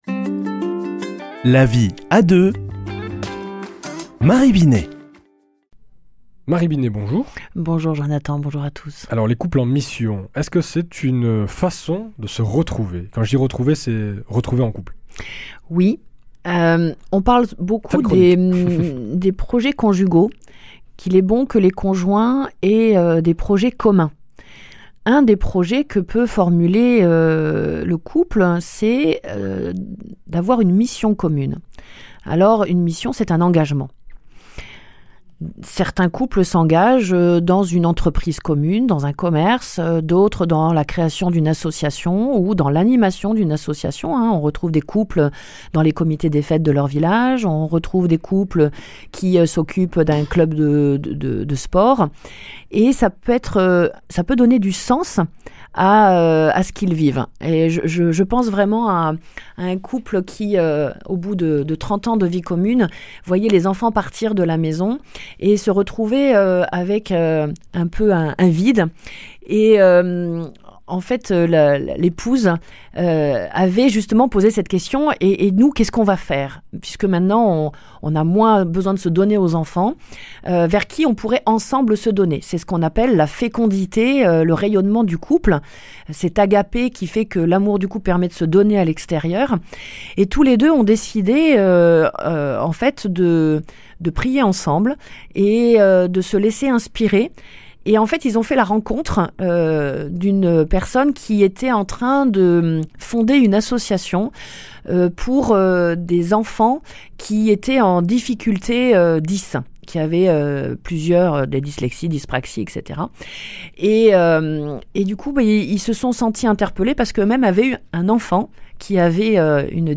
mardi 25 février 2025 Chronique La vie à deux Durée 4 min